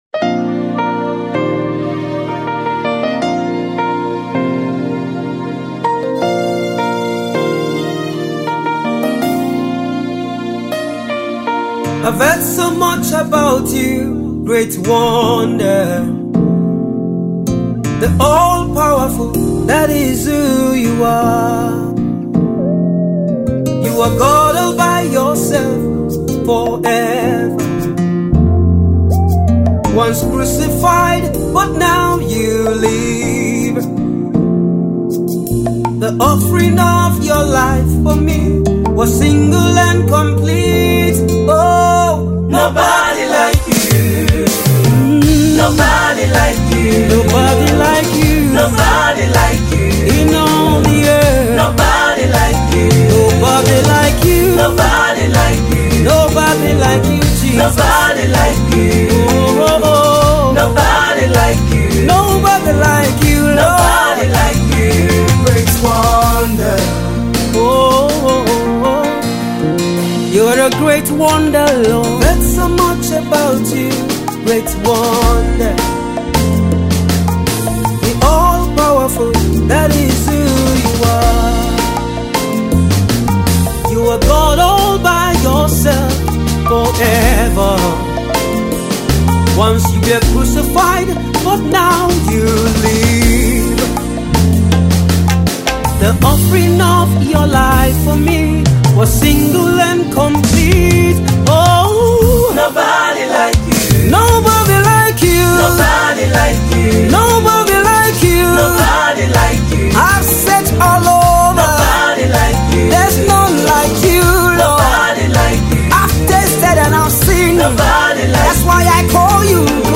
Indie gospel artiste